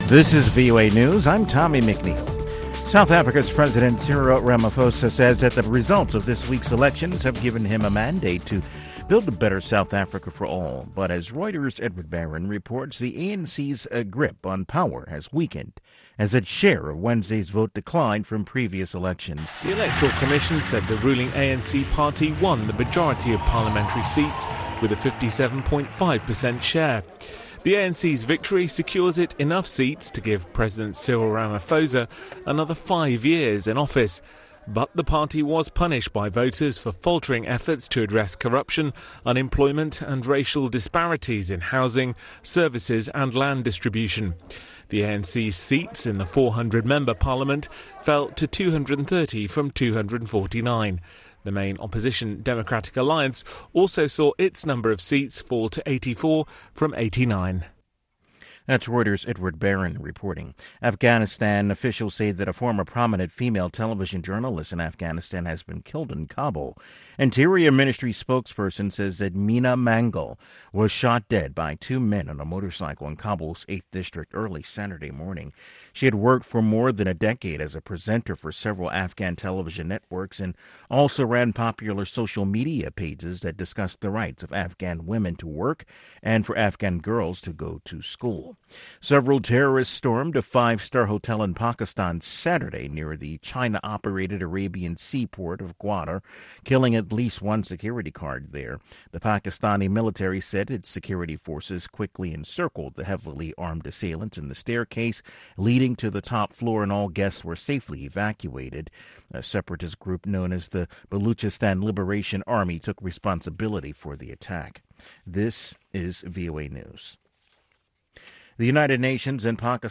Starting at :05 into the hour today's edition features music about, by, and for mothers in honor of Mother's Day.
Malian Diva Oumou Sangaré is our featured guest this week!